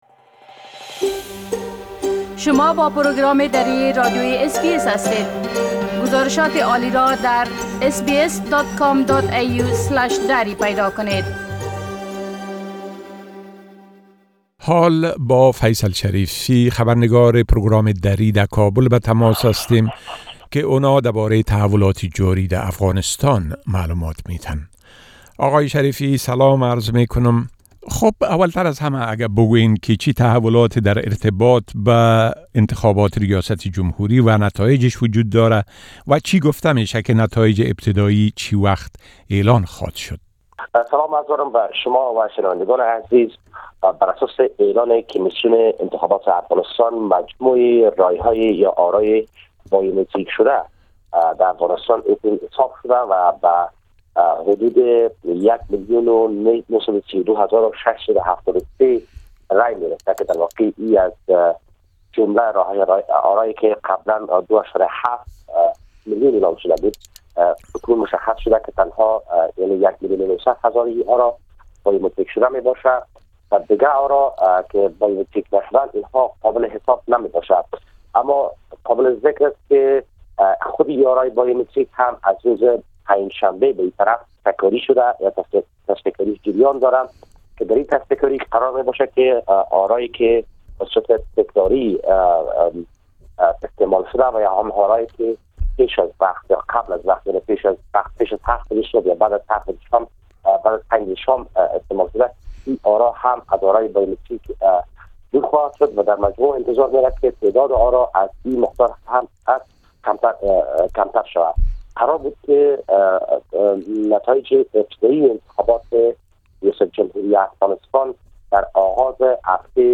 A report from our correspondent in Afghanistan which can be heard here in Dari language